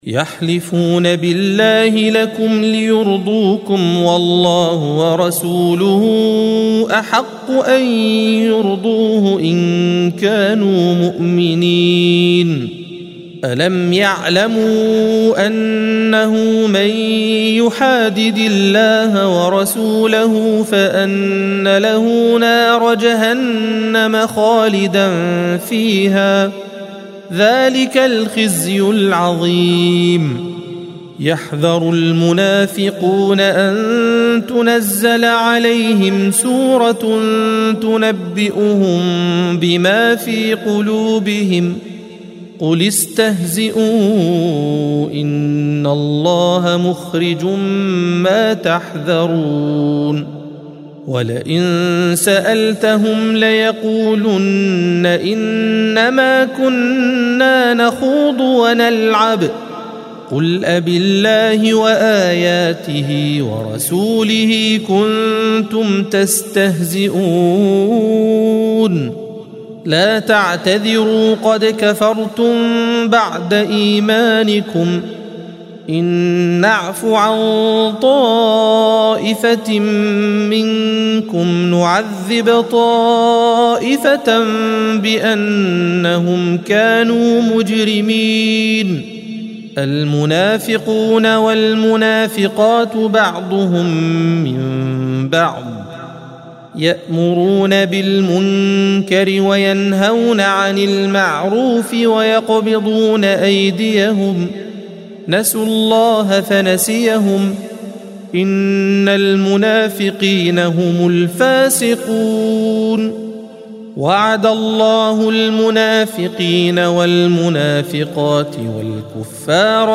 الصفحة 197 - القارئ